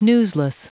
Prononciation du mot newsless en anglais (fichier audio)
newsless.wav